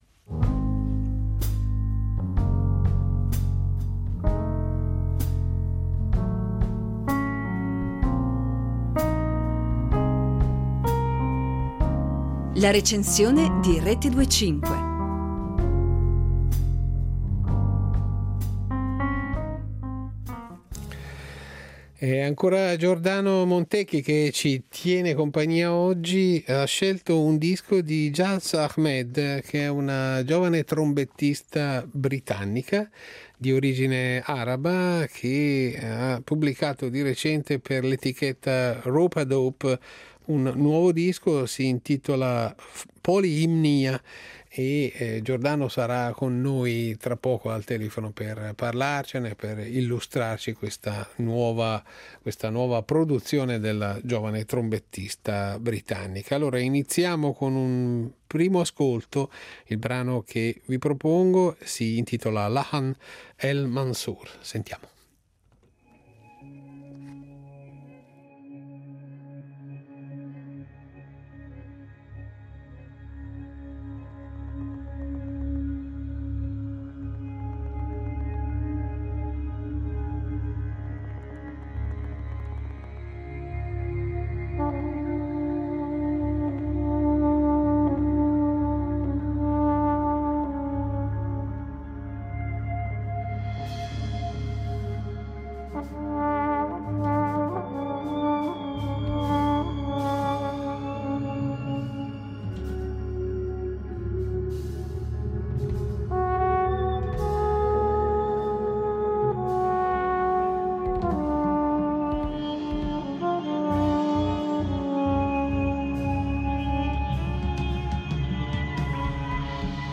La Recensione
Jazz sì, ma intriso di colori e sapori davvero speciali.